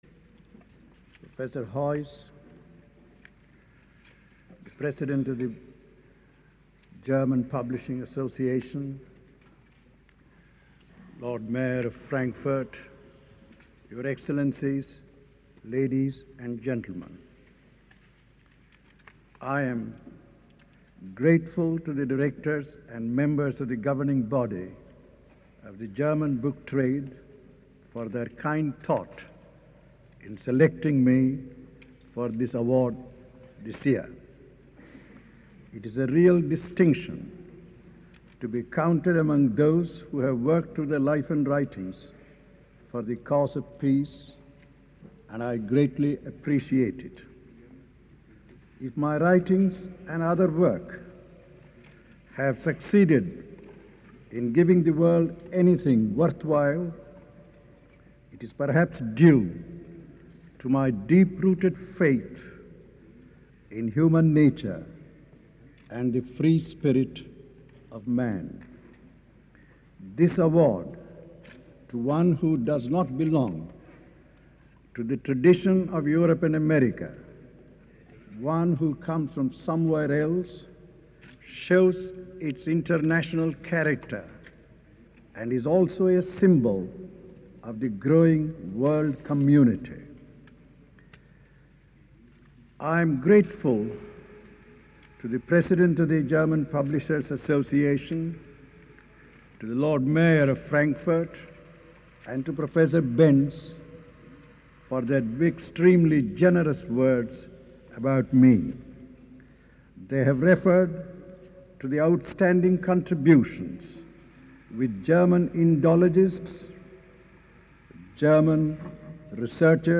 FP_1961_Friedenspreisrede_Sarvepalli_Radhakrishnan.mp3